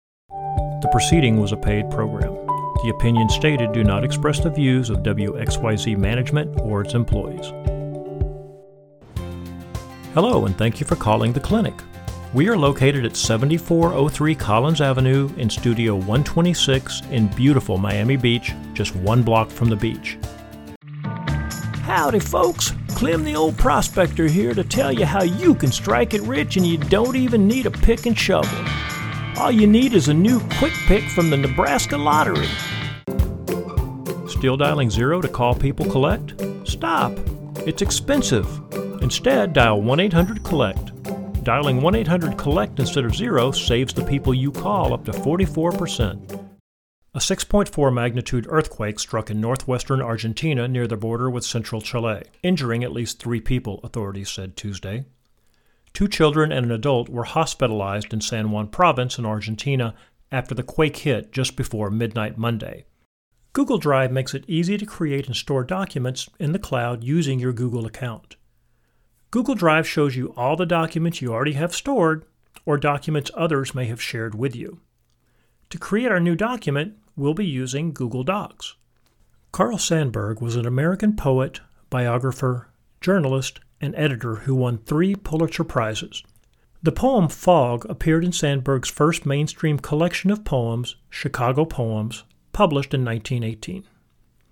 Originally from Texas, I have a faint accent that can be amped up when needed.
This encompasses all aspects of my skill set: commercial, character, narration, e-learning and corporate voices in one audio file.
Combination-Demo-Reel.mp3